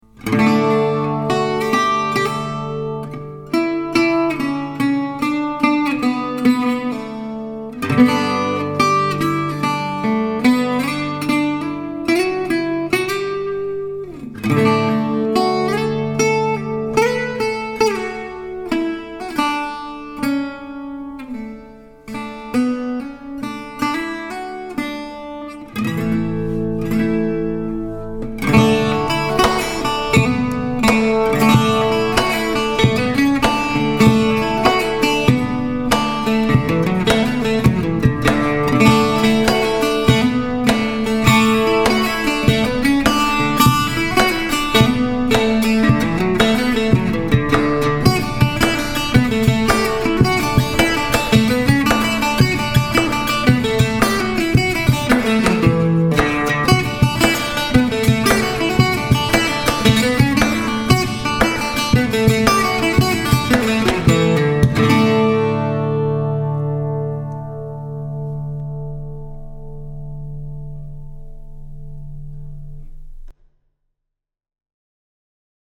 This was played on my Gypsy Jazz grande bouche bouzouki-guitar (it's a complex beastie!), and yes, that also includes the percussion part.